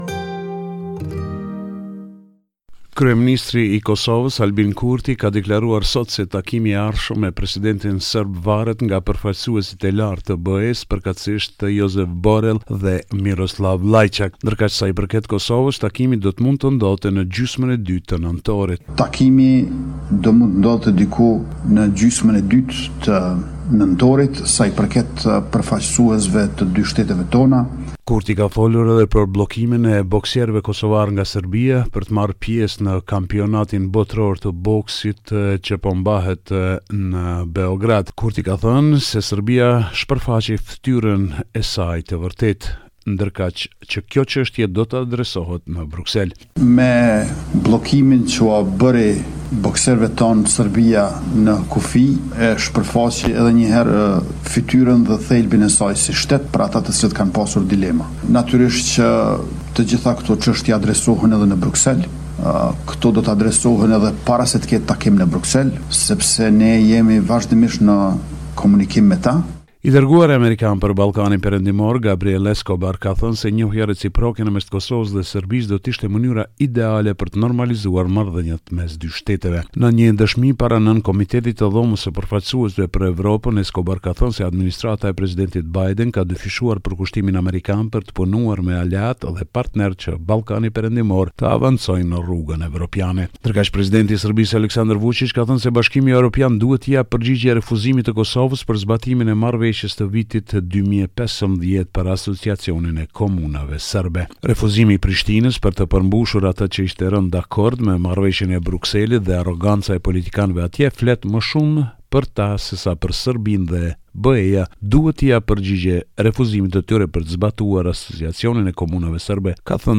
This is a report summarising the latest developments in news and current affairs in Kosova.